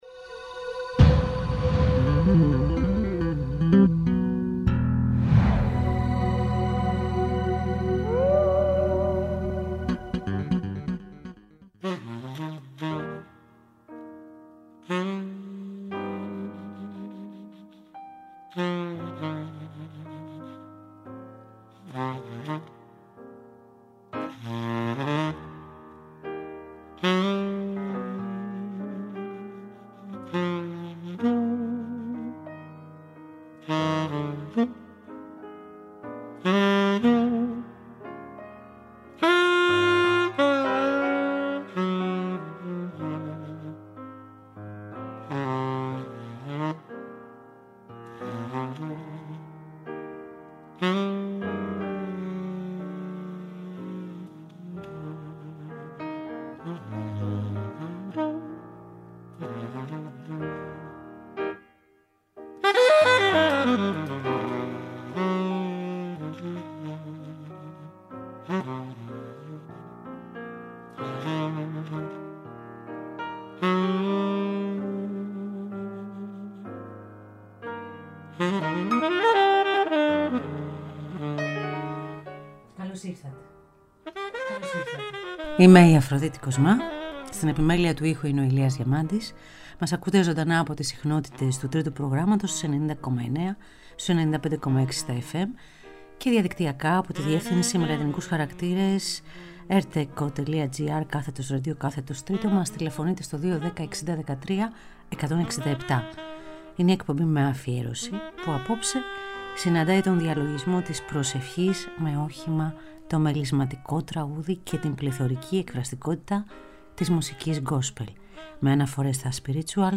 Η εκπομπή Με Αφιέρωση συναντάει τον διαλογισμό της προσευχής με όχημα το μελισματικό τραγούδι και την πληθωρική εκφραστικότητα της μουσικής gospel με αναφορές στα spirituals και στα blues.
Ζωντανά από το στούντιο του Τρίτου Προγράμματος.